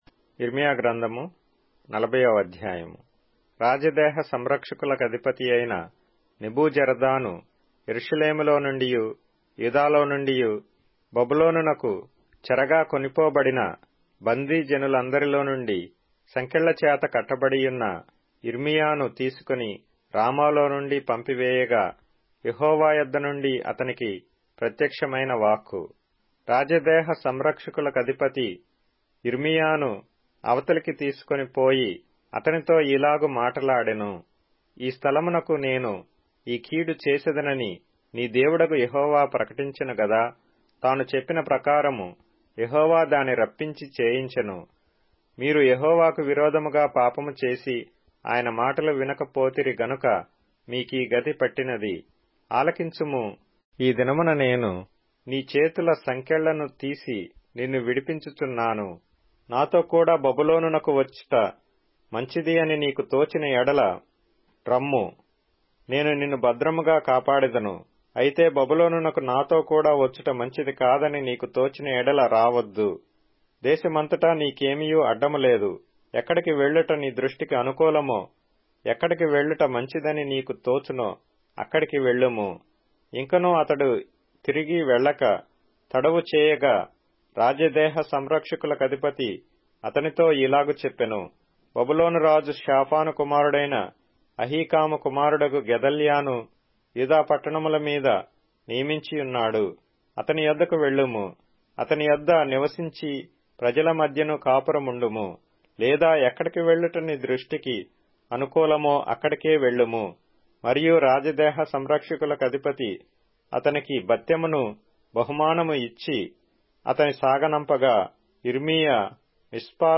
Telugu Audio Bible - Jeremiah 31 in Orv bible version